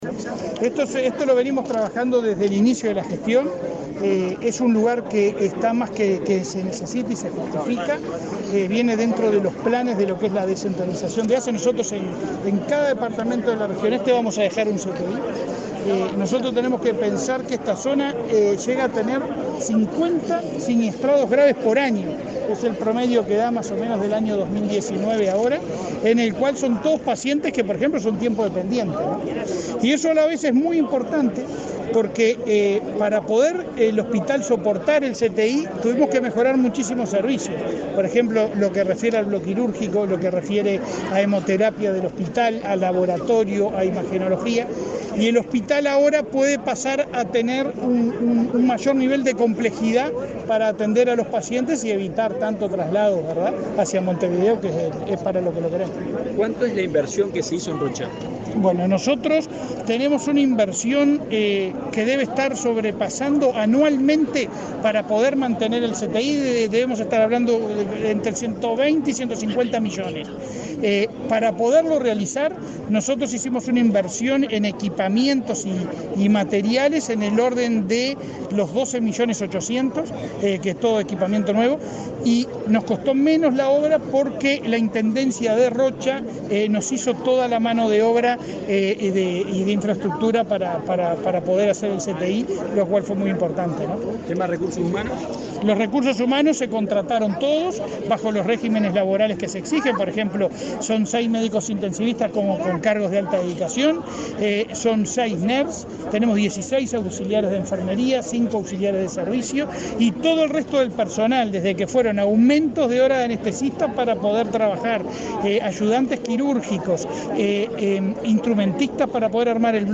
Declaraciones del presidente de ASSE, Leonardo Cipriani
El presidente de ASSE, Leonardo Cipriani, dialogó con la prensa acerca de las características del centro de terapia intensiva (CTI) y el servicio de